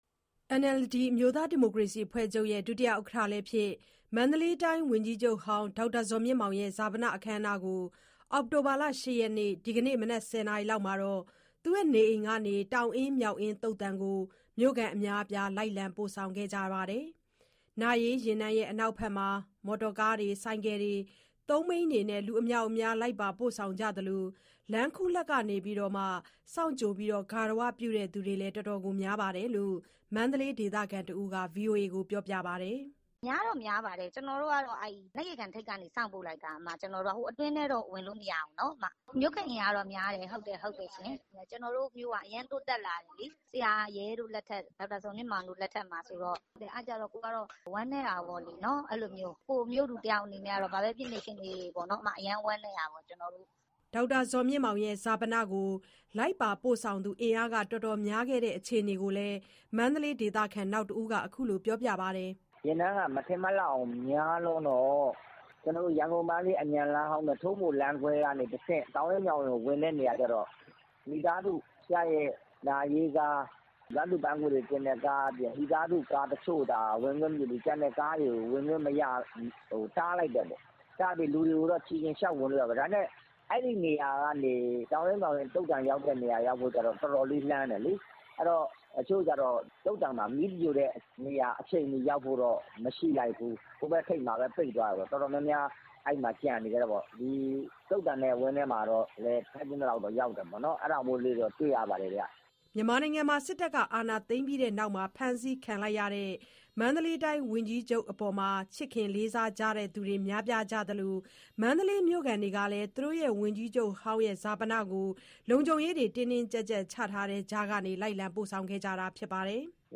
ဈာပနလိုက်ပို့သူအင်အား တော်တော်များခဲ့တဲ့ အခြေအနေကိုလည်း မန္တလေးဒေသခံ နောက်တဦးက အခုလိုပြောပါတယ်။